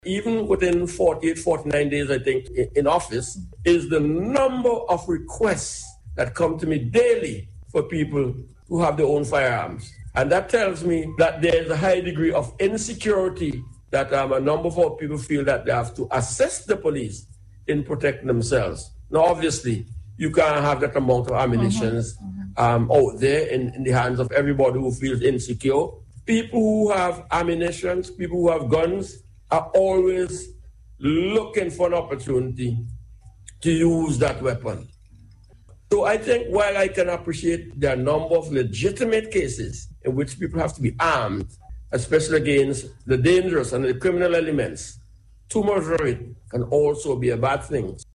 This was revealed by Deputy Prime Minister and Minister of National Security, Hon. St. Clair Leacock on Radio yesterday.